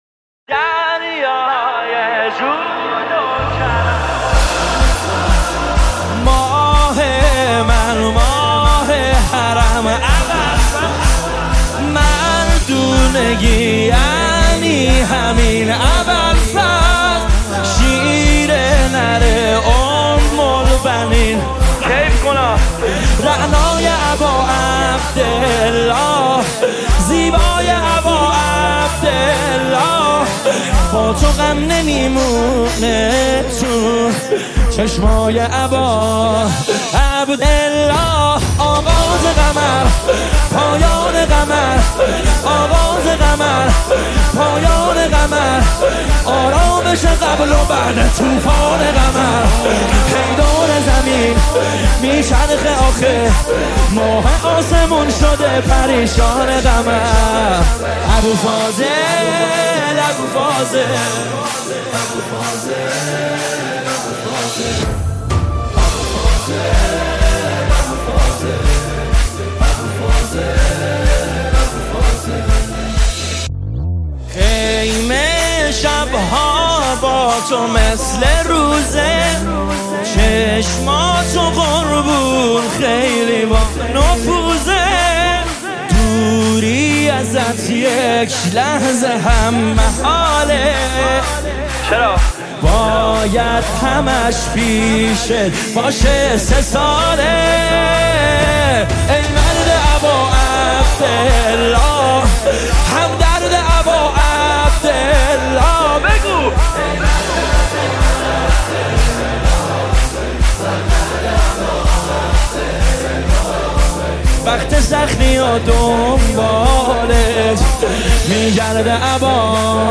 نماهنگ استودیویی - دریای جود و کرم ابالفضل